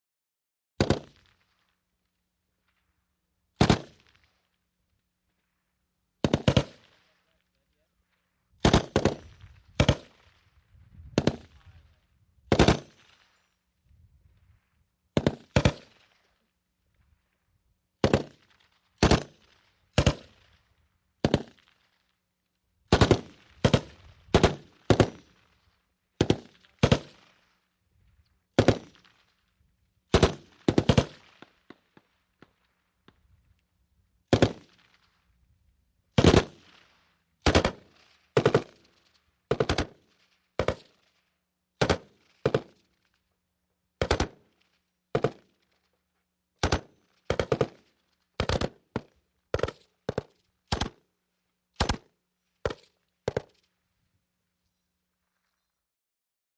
BritMachGunFire PE706901
WEAPONS - MACHINE GUNS HEAVY BRITISH GIMPY: EXT: Multiple bursts of two rounds, quick echos.